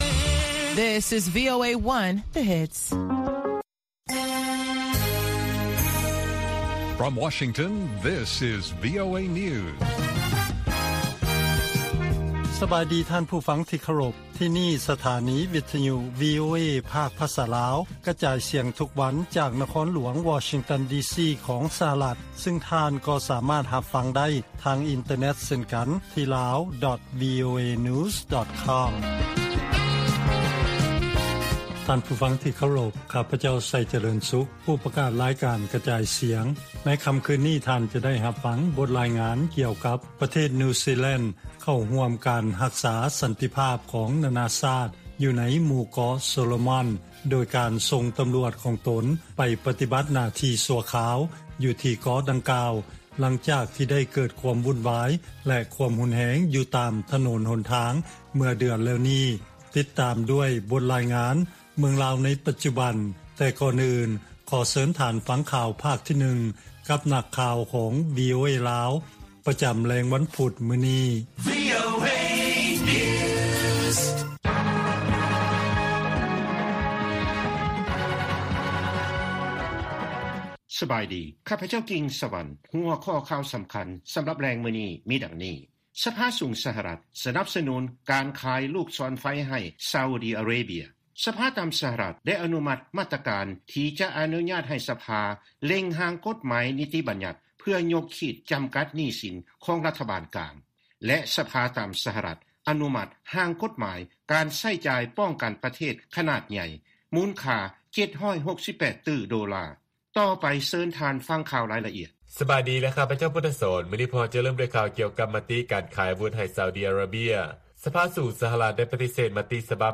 ວີໂອເອພາກພາສາລາວ ກະຈາຍສຽງທຸກໆວັນ, ຫົວຂໍ້ຂ່າວສໍາຄັນໃນມື້ນີ້ມີ: 1.